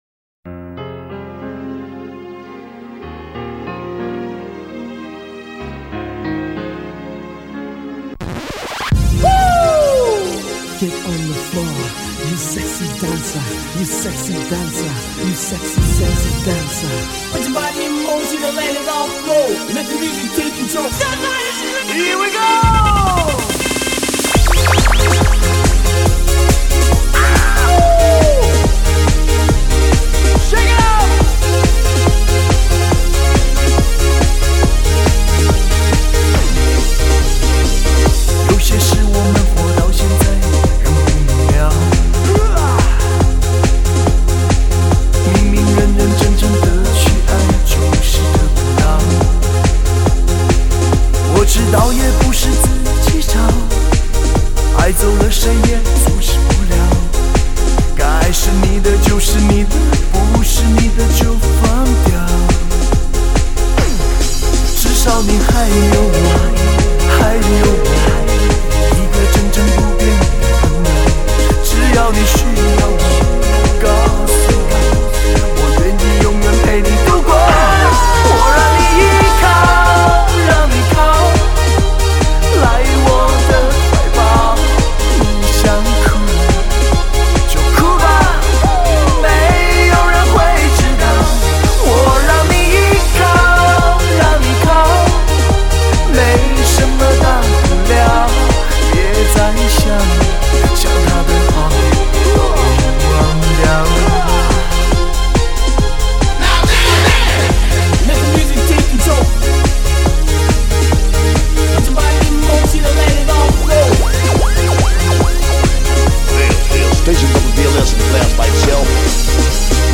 原创新版国语 REMIX 概念合辑